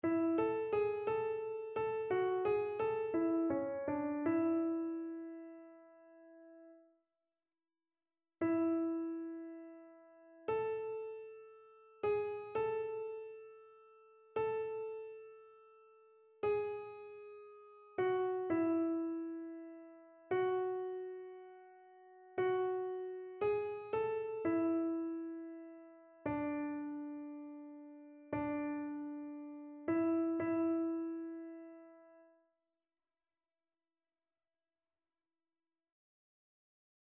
annee-c-temps-ordinaire-19e-dimanche-psaume-32-soprano.mp3